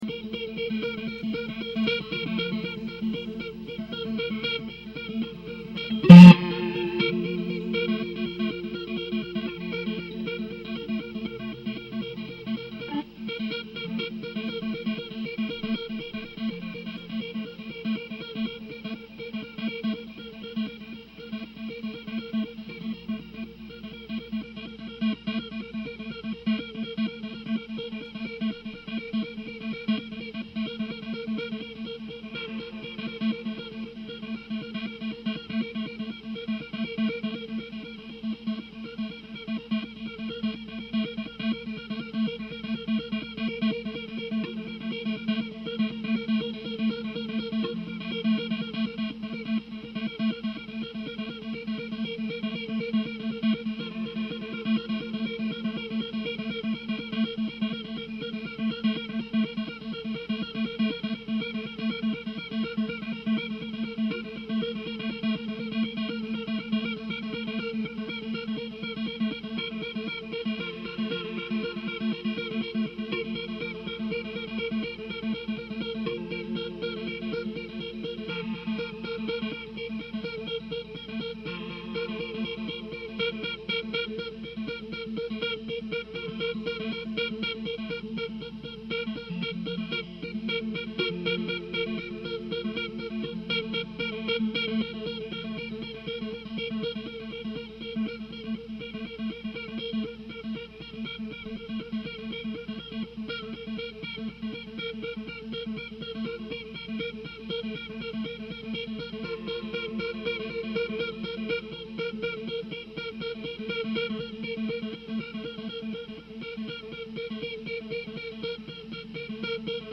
Recorded live at home in Manhattan.
Mono (Pro Tools) Alarm Clock Oasis
guitar Mono